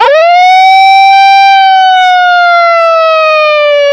Play, download and share Wail Louder! original sound button!!!!
wail-up.mp3